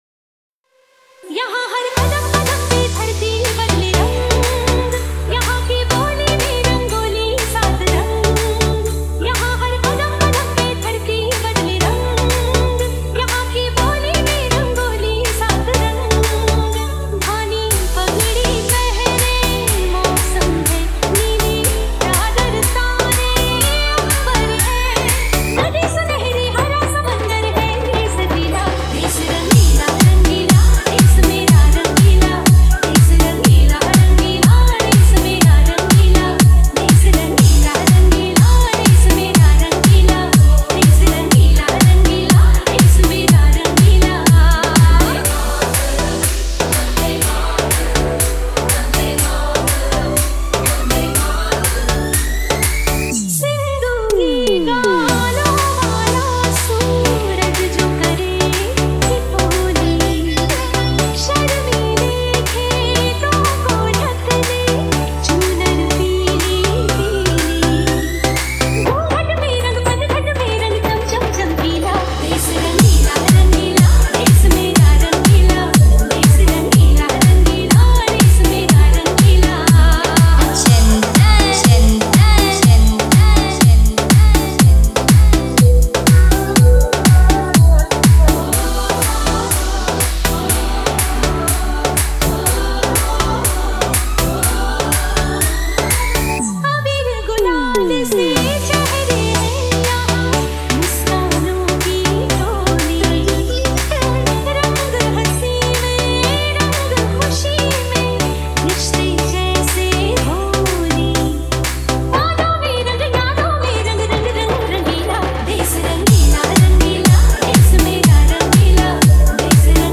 Hindi Dj Song